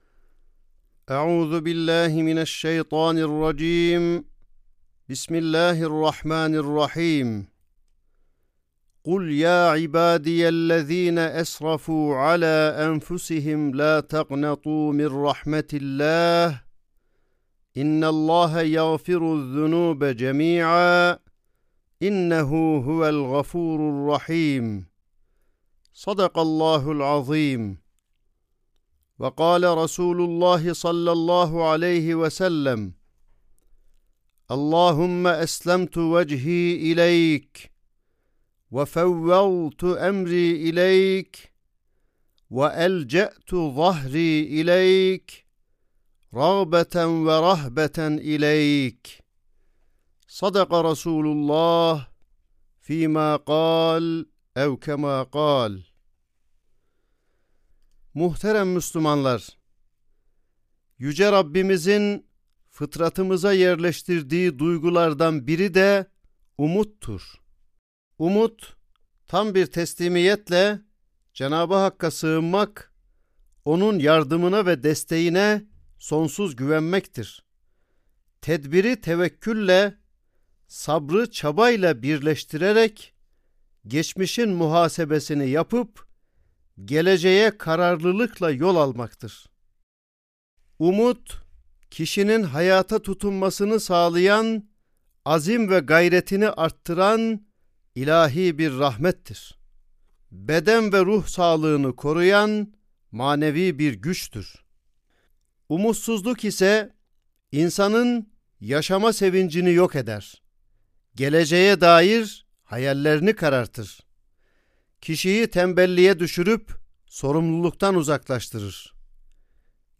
Sesli Hutbe (Müminin Hayatında Umutsuzluğa Yer Yoktur).mp3